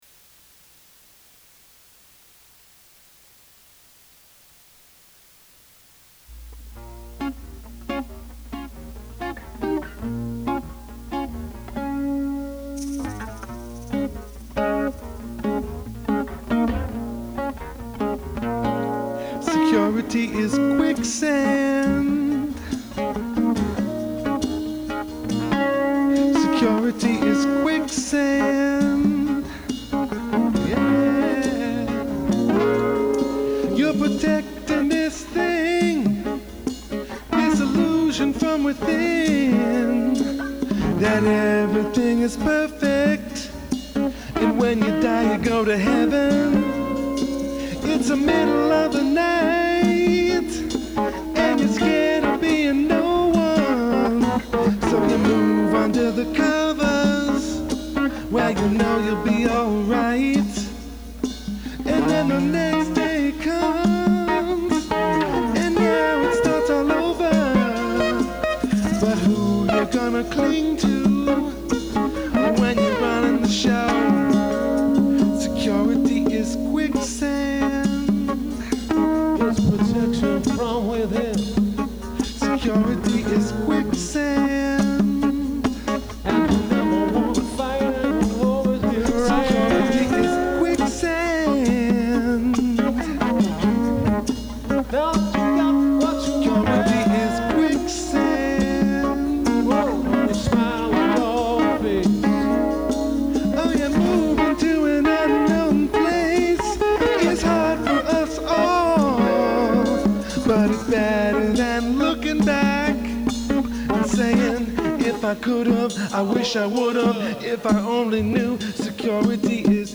Audio | 90’s unplugged